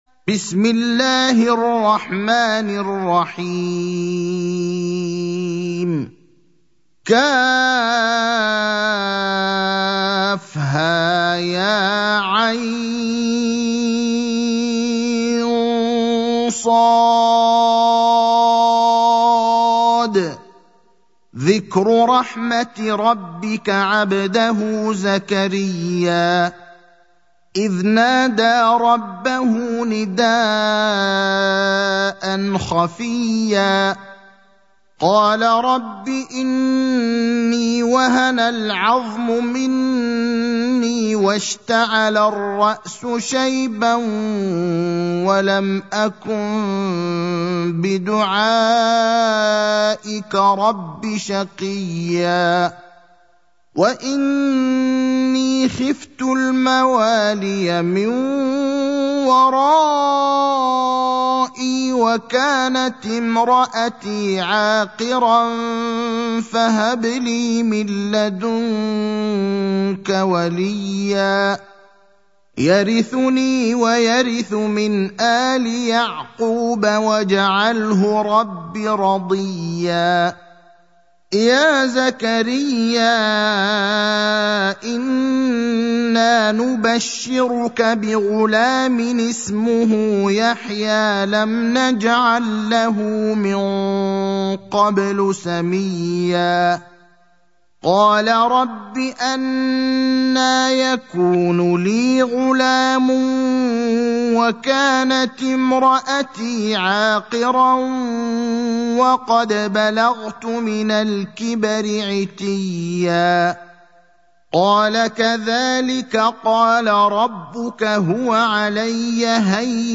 المكان: المسجد النبوي الشيخ: فضيلة الشيخ إبراهيم الأخضر فضيلة الشيخ إبراهيم الأخضر مريم (19)